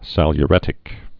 (sălyə-rĕtĭk)